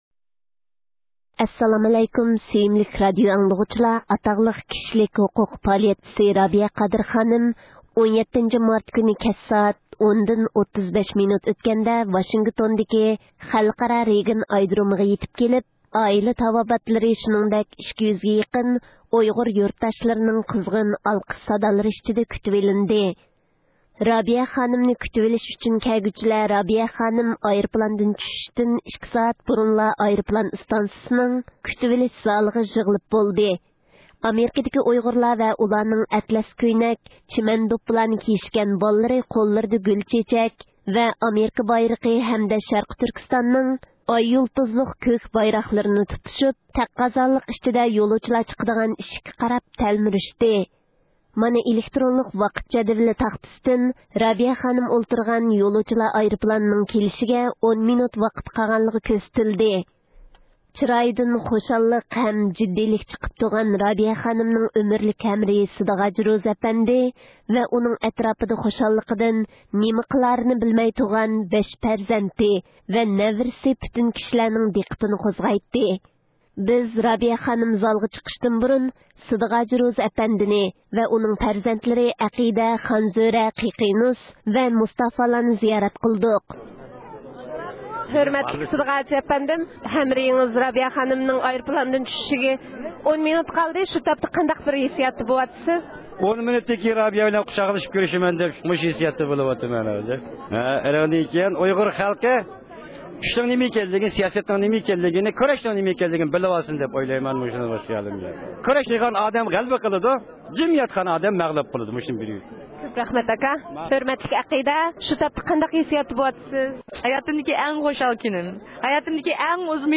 ئاتاقلىق كىشىلىك ھوقۇق پائالىيەتچىسى رابىيە قادىر خانىم، 17 – مارت كۈنى كەچ سائەت 35 : 10 مىنۇت ئۆتكەندە، ۋاشىنگتوندىكى رېگان خەلقئارا ئايرودۇرۇمىغا يېتىپ كېلىپ، ئائىلە تاۋاباتلىرى شۇنىڭدەك ئۇيغۇر قېرىنداشلىرىنىڭ قىزغىن ئالقىش سادالىرى ئىچىدە كۈتۈۋىلىندى.